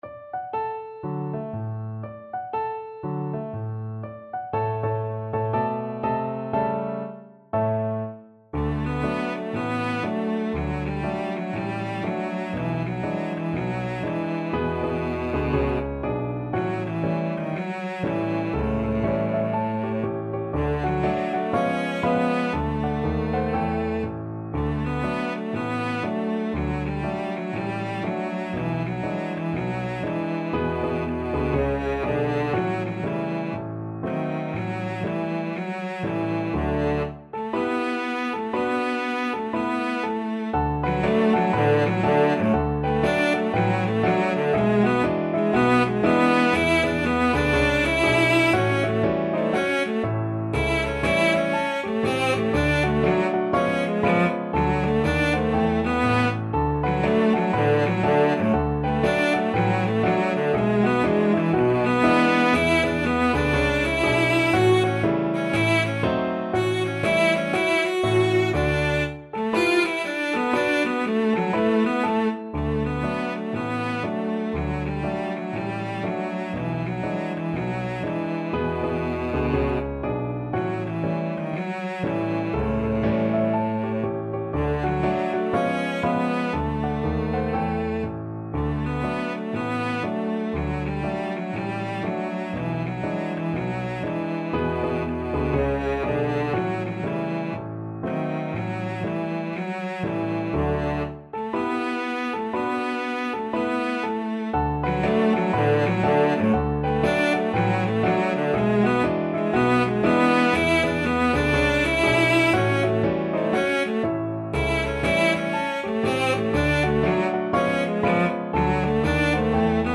Cello version
4/4 (View more 4/4 Music)
= 120 Moderato